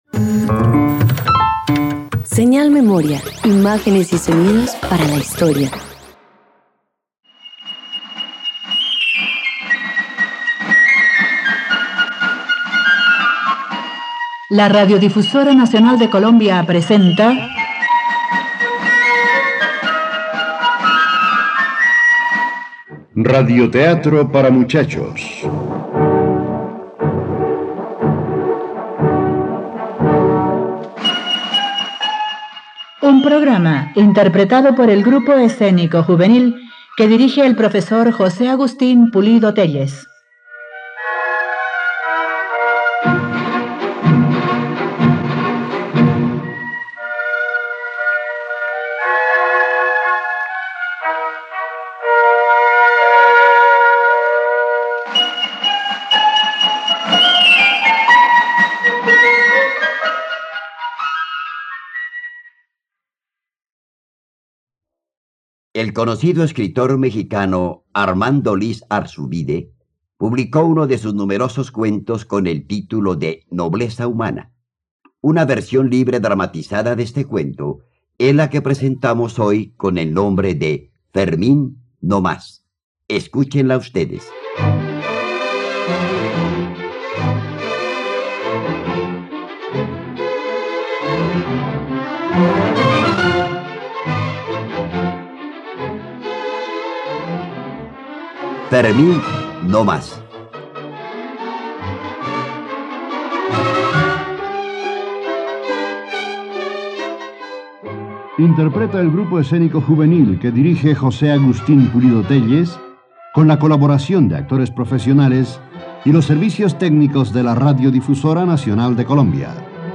Fermín, no más - Radioteatro dominical | RTVCPlay